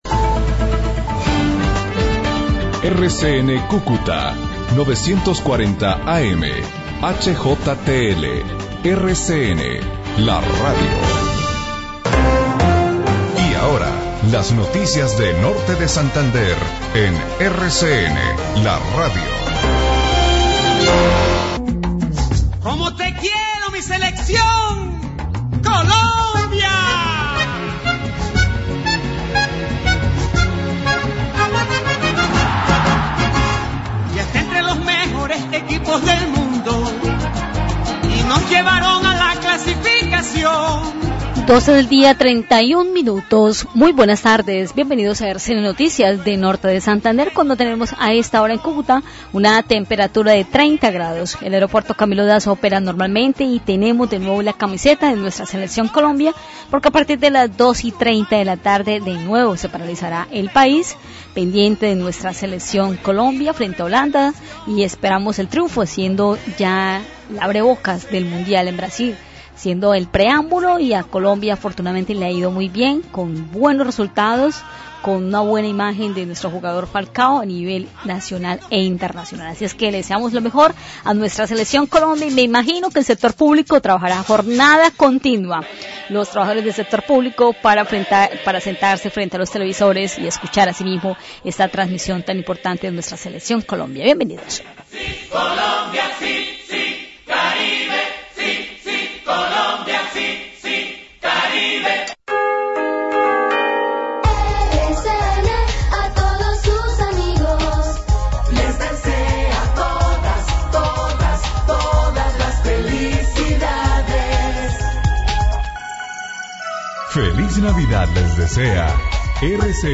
El Noticiero RFN Noticias!